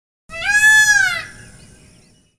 Pavo Real Indio (Pavo cristatus)